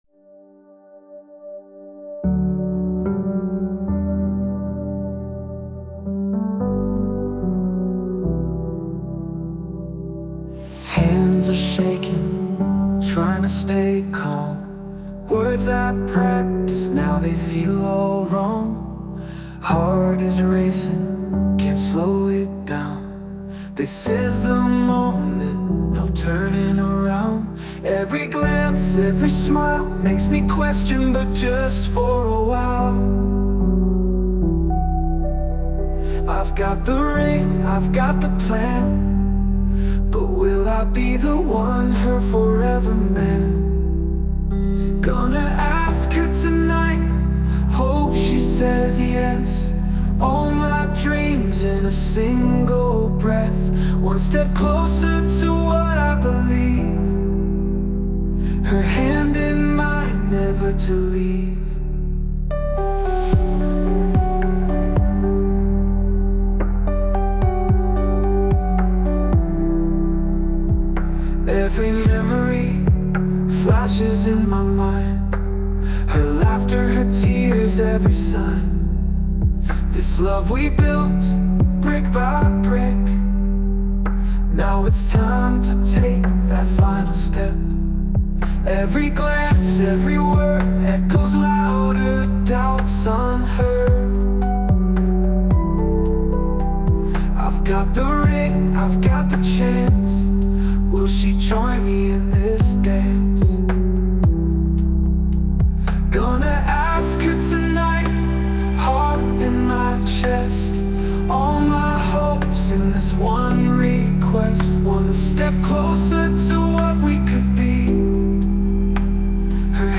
洋楽男性ボーカル著作権フリーBGM ボーカル
著作権フリーオリジナルBGMです。
男性ボーカル（洋楽・英語）曲です。
プロポーズ前の男性の心境を、スローでネイチャー系の音楽にのせて表現しました。